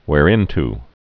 (wâr-ĭnt, hwâr-)